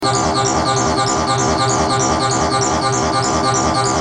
Sons Roland Tb303 -3
Basse tb303 - 51